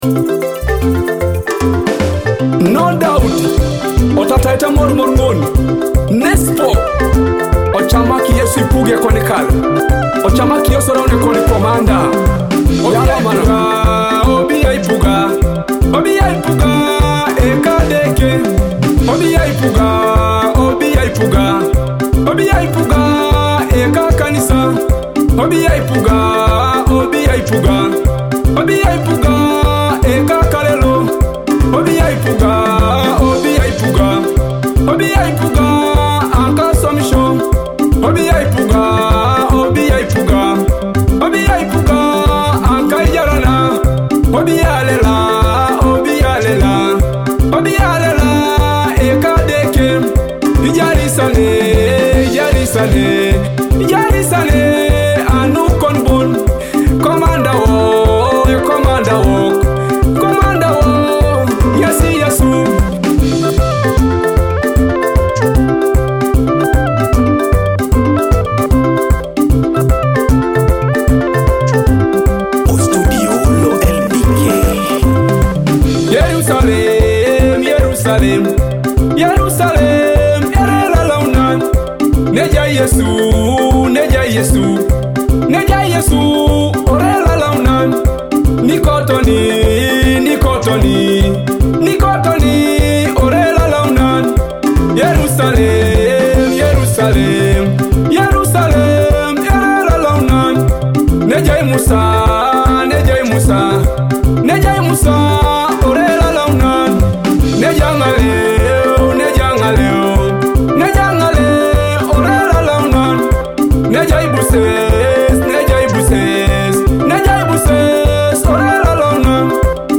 gospel hit